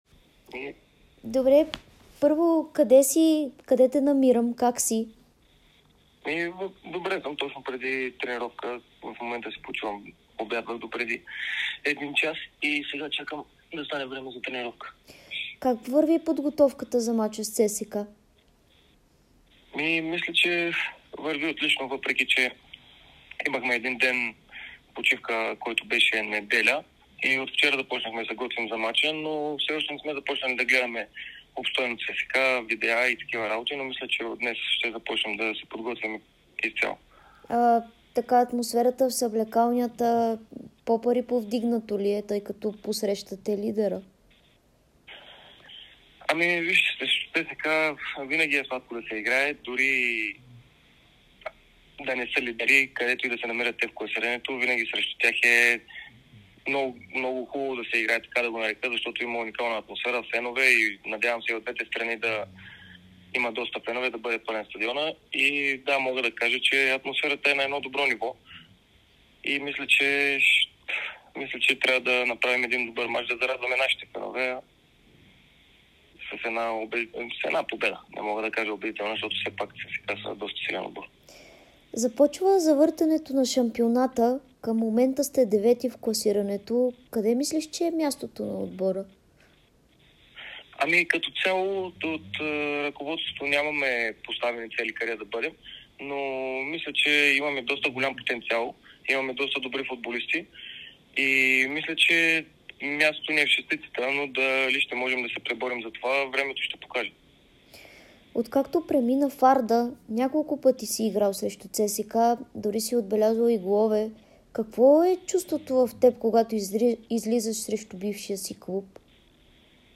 ексклузивно интервю